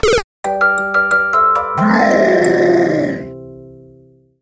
A Bowser variant of an arrangement